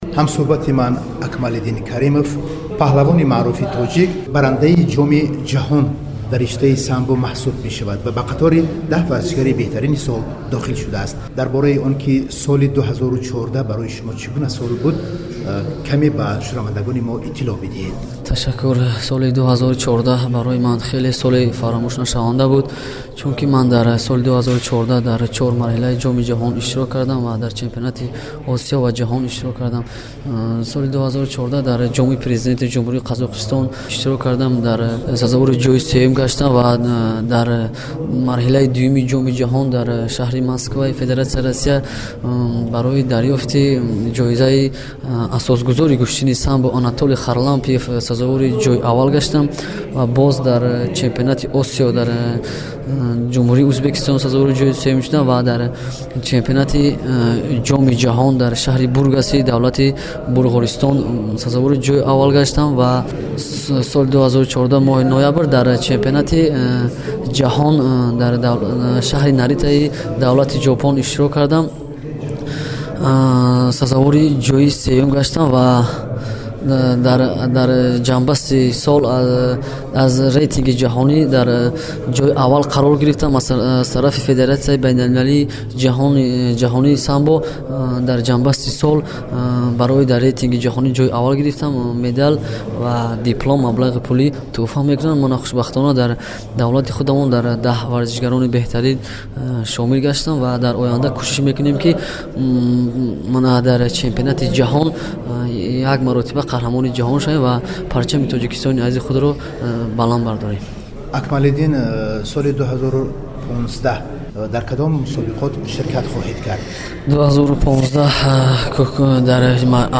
Сӯҳбат